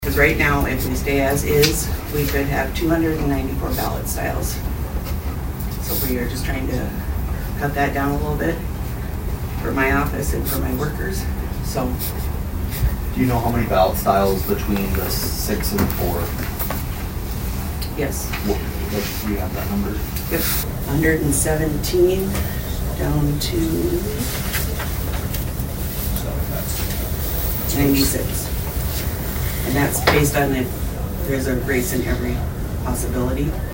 ABERDEEN, S.D.(HubCityRadio)- At Tuesday’s Brown County Commission meeting, the commissioners received a report from Brown County Auditor Lyn Heupel talking about the number of precincts within the city of Aberdeen.